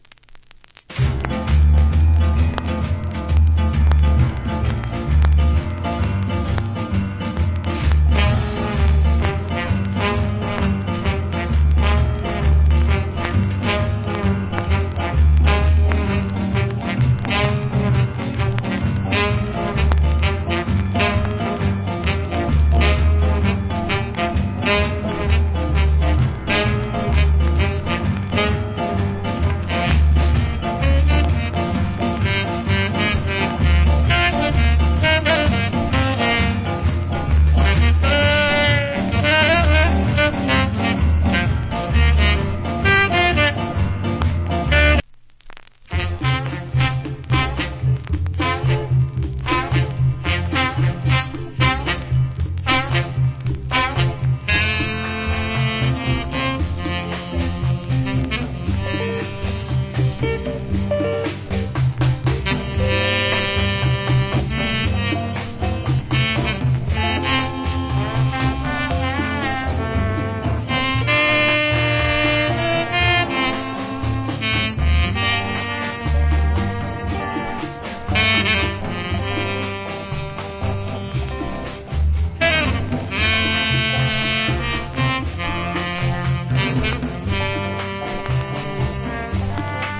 Early Ska Inst.